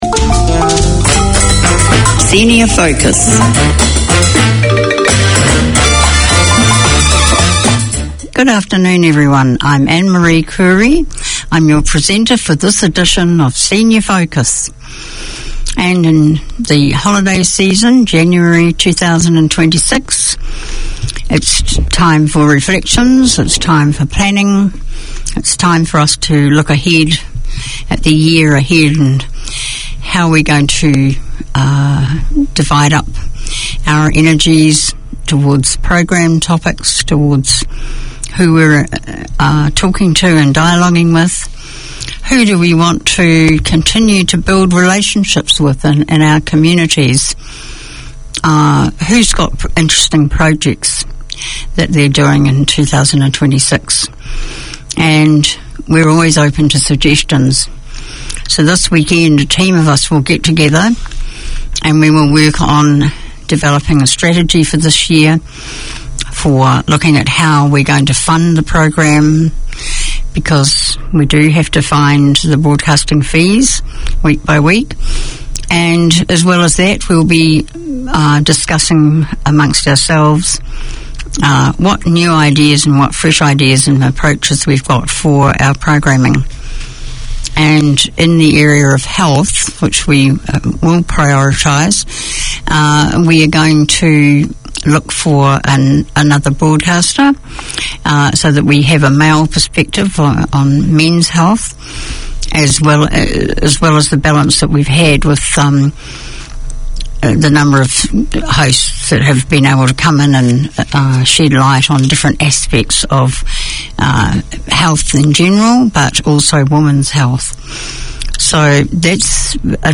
From local legends in grassroots to national level names, the Sports Weekender features interviews, updates and 'the week that was'.